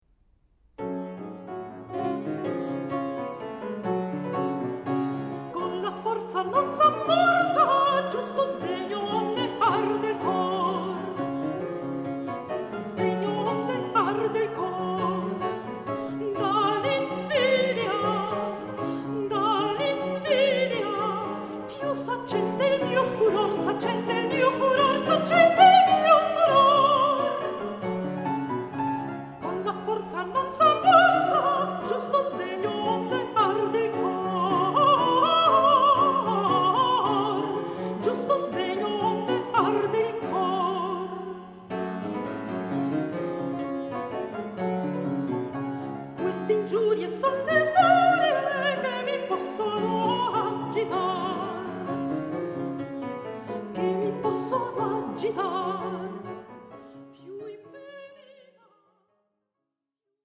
for soprano, traverse and basso continuo